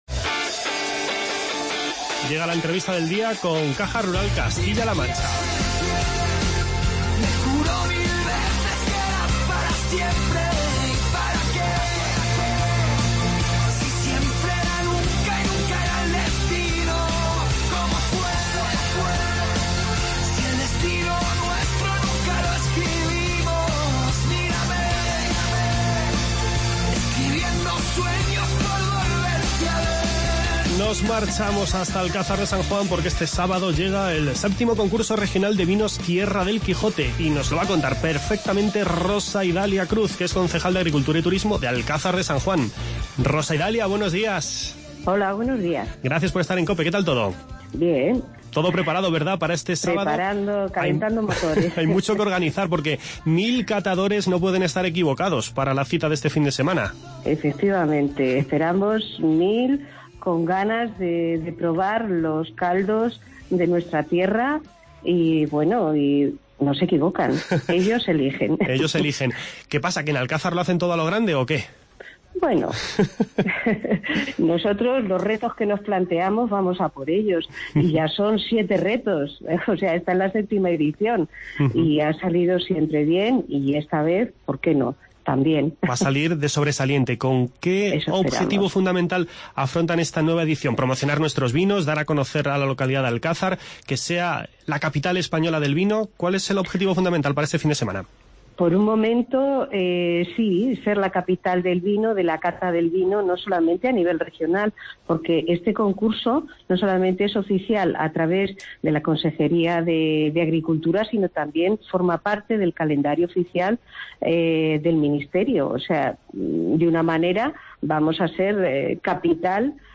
Hoy charlamos con Rosa Idalia Cruz, concejal de Turismo del Ayuntamiento de Alcázar de San Juan, sobre el VII Concurso Regional "Vinos Tierra del Quijote". Además, nos vamos con Francisco Martín Simón, director general de Turismo de la Junta de Extremadura, hasta la Feria Internacional de Turismo Ornitológico que se celebra este fin de semana en el Paque Nacional de Monfragüe.